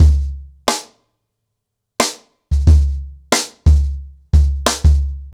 CornerBoy-90BPM.19.wav